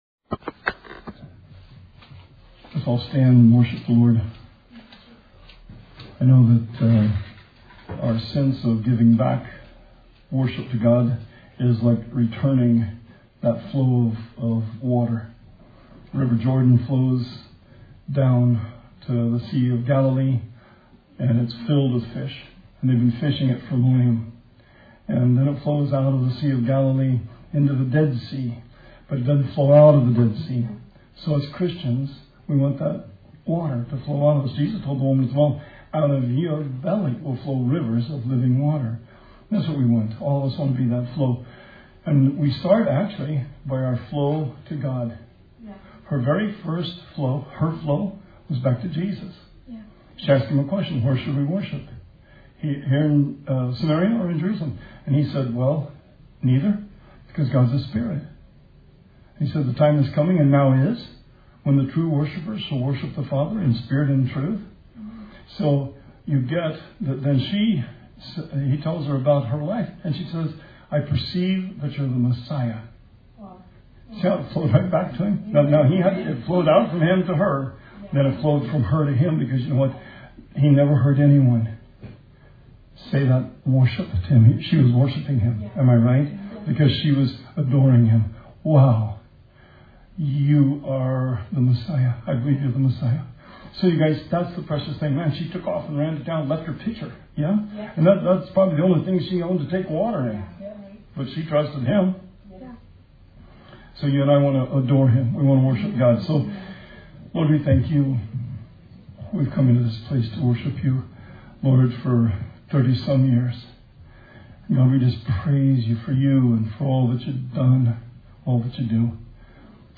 Sermon 5/10/20